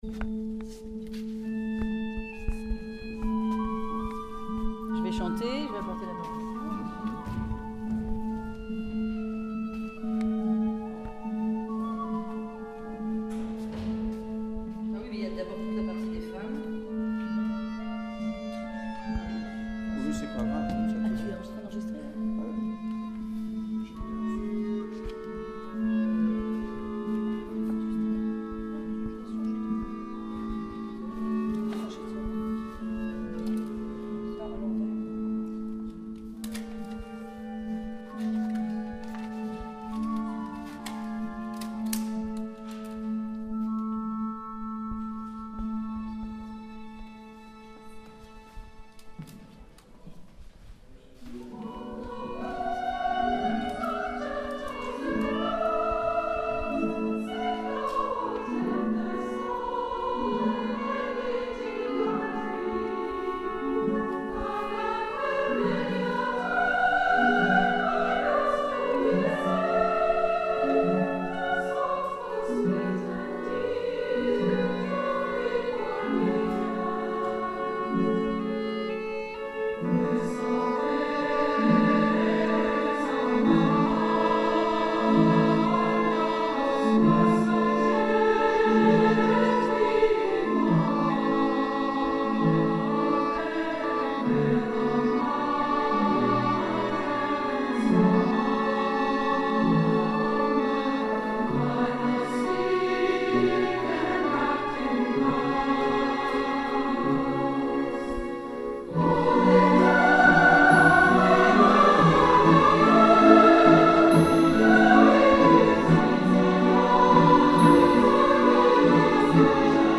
borodin_basse_orchestre.mp3